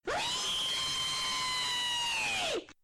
Reeeeeee Scream Sound Effect Free Download
Reeeeeee Scream